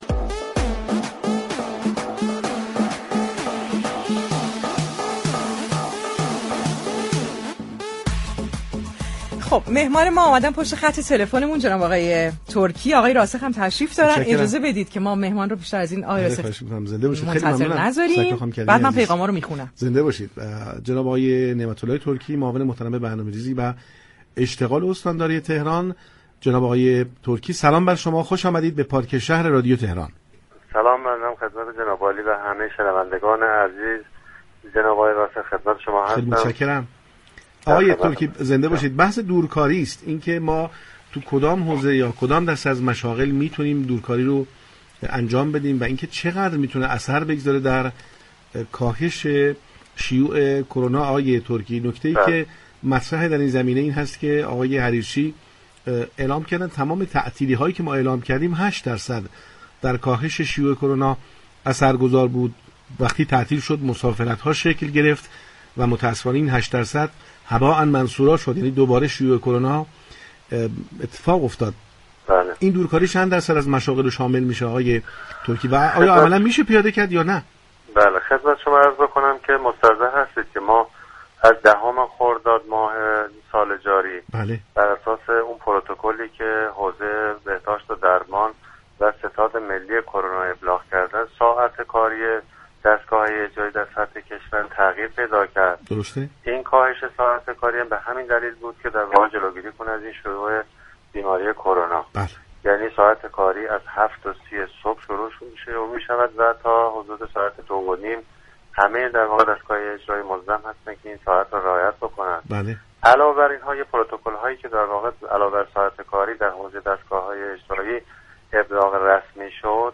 وی در انتهای این گفتگوی تلفنی تأكید كرد: بیشترین كاری كه می‌توان در این رابطه انجام داد رعایت پروتكل‌ها از سوی خود مردم است.